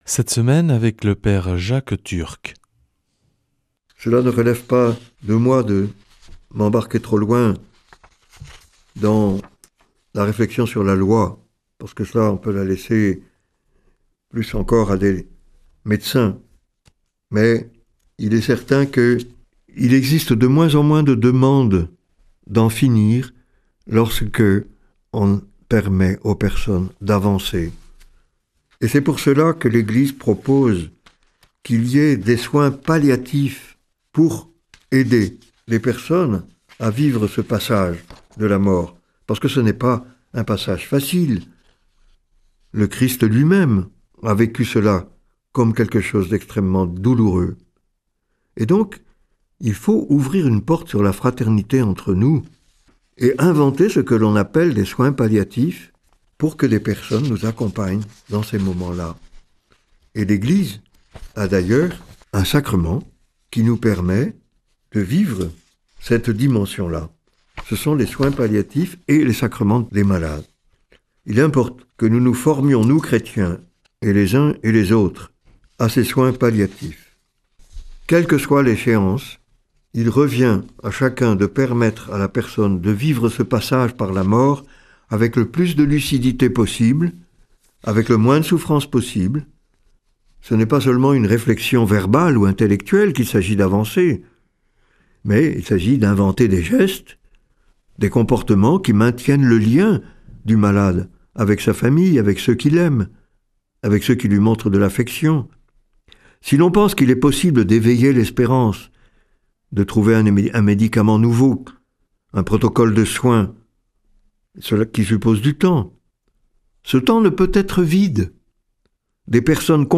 vendredi 27 février 2026 Enseignement Marial Durée 10 min